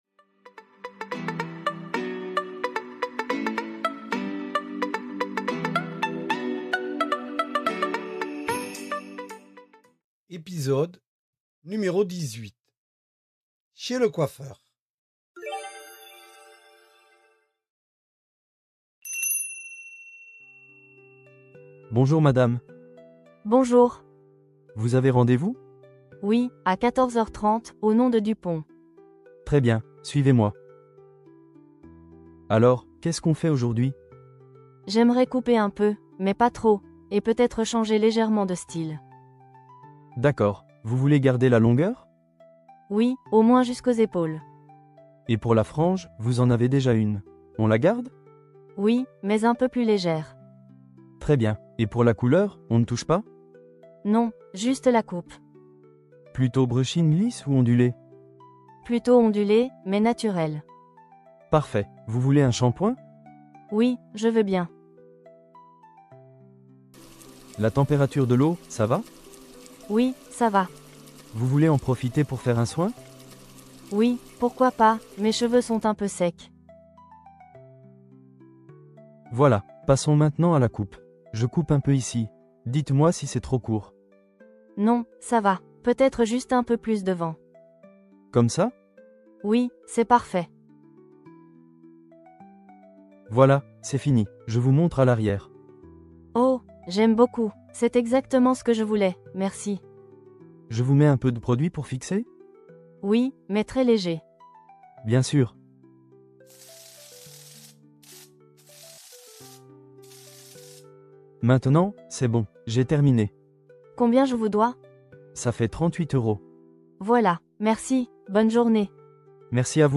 Voici un dialogue pour les élèves de niveau A1 sur une conversation dans un salon de coiffure.